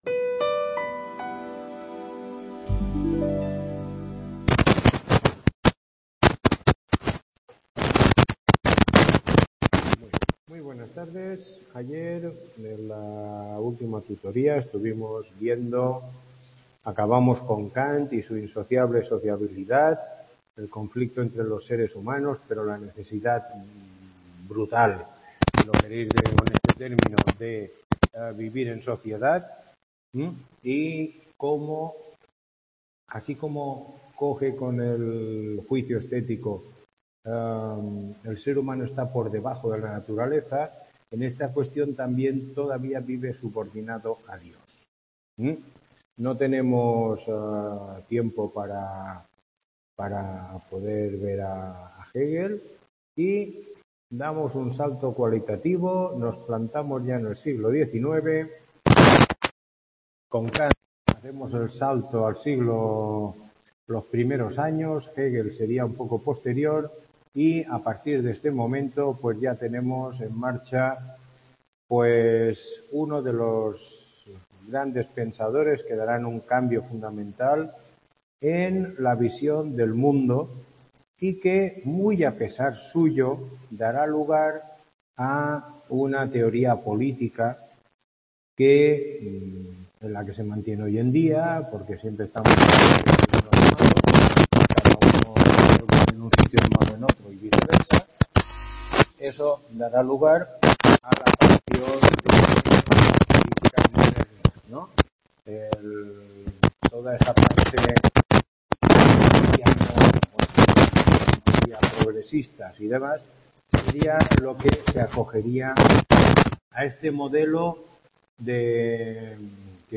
Tutoría 04